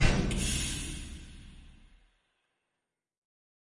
描述：使用来自freesound的声音重新制作光环needler步枪。中等范围的火声
标签： 科幻 激光 刺针
声道立体声